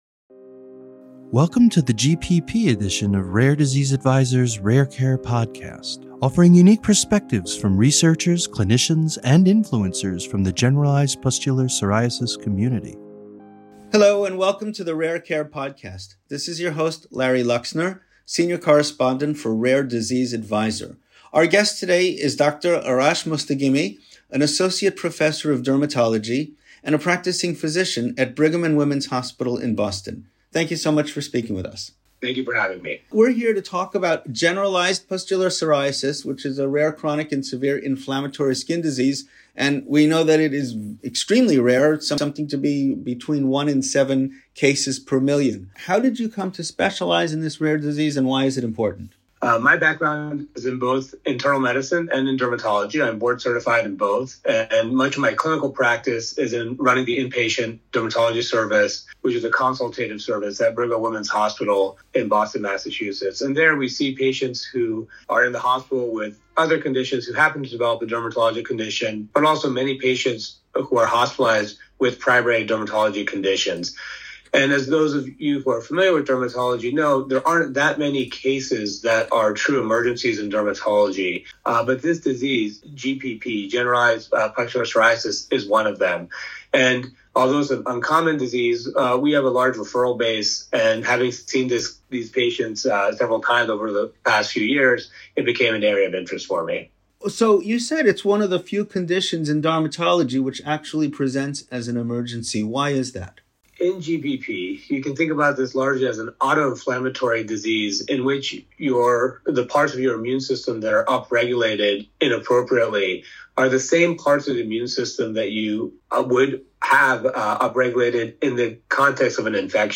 Rare Care Podcast / An Interview With Generalized Pustular Psoriasis Expert